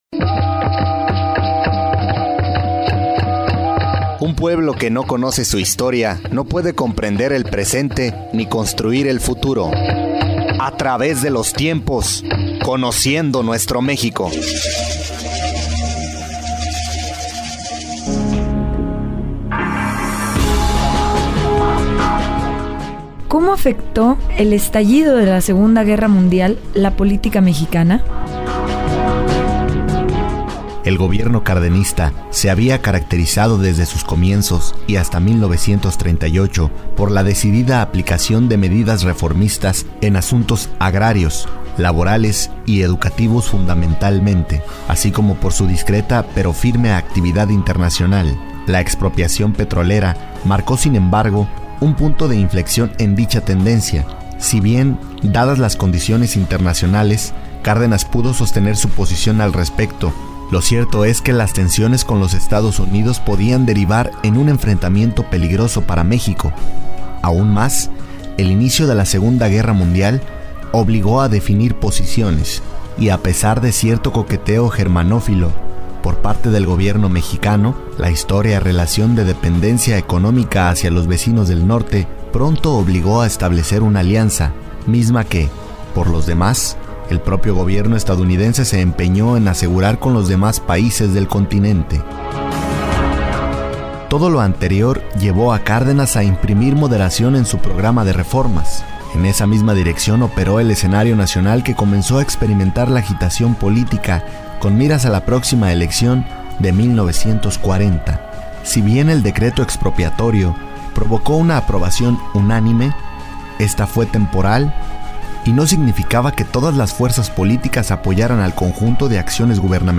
PROGRAMA RADIOFONICO DE VALORES CHARLEMOS